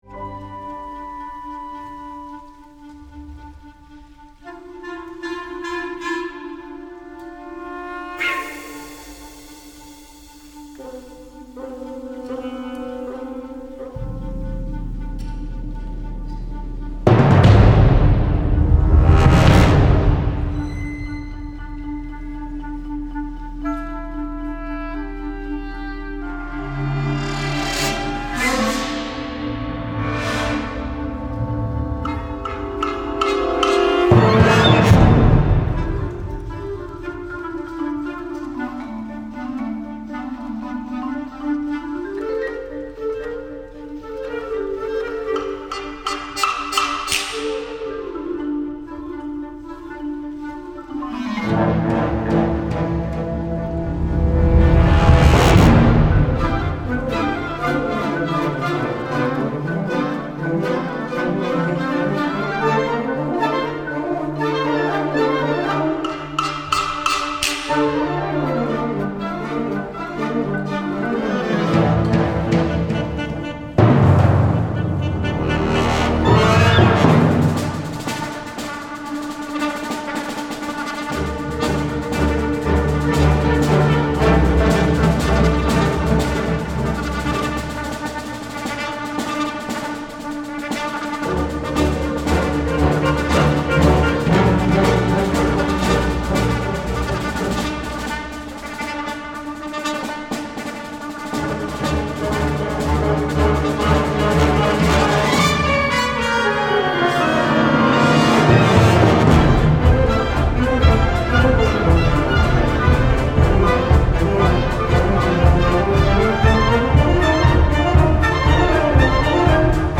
Gattung: Filmmusik für Blasorchester
Besetzung: Blasorchester